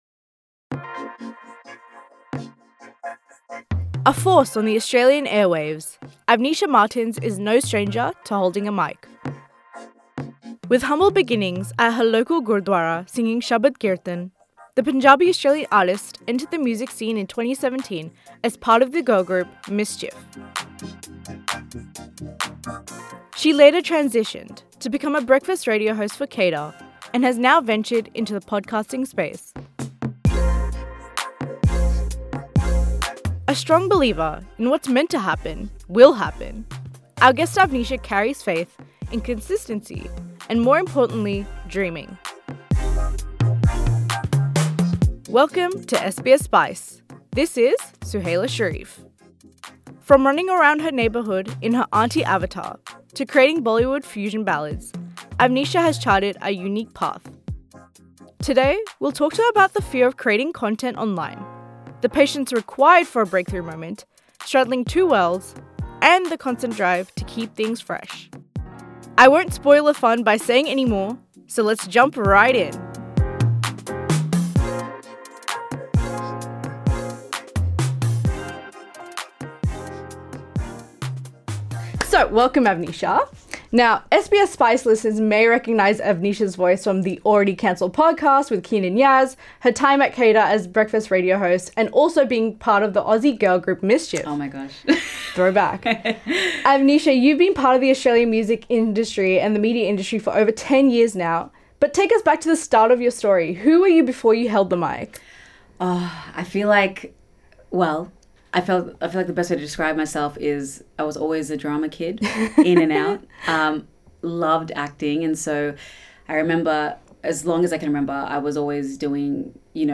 Tap the audio player to listen to the full interview. SBS Spice breaks new ground with English language content for young South Asians in Australia by exploring what is making us tick or ick.